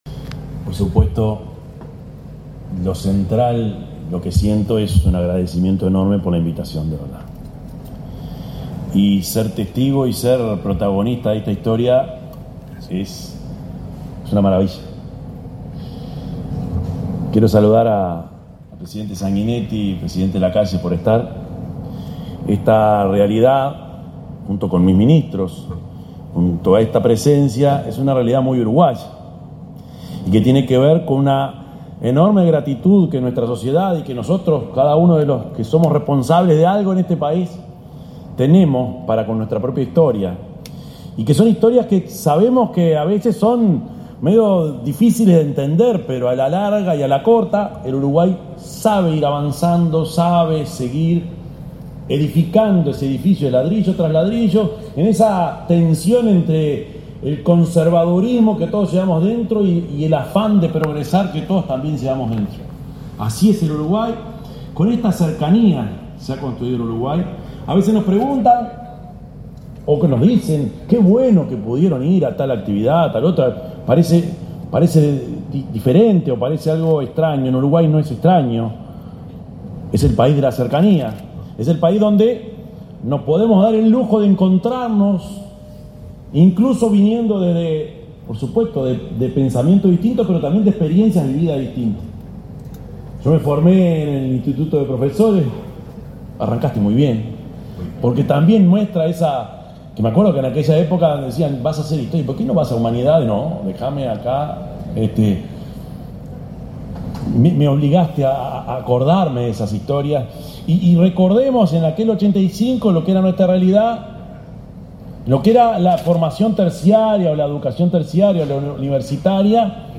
Palabras del presidente de la República, profesor Yamandú Orsi
El presidente de la República, profesor Yamandú Orsi, participó, este 18 de marzo, en la celebración del 40.° aniversario de la Universidad Católica